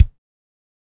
Index of /90_sSampleCDs/Northstar - Drumscapes Roland/KIK_Kicks/KIK_A_C Kicks x
KIK A C K0FR.wav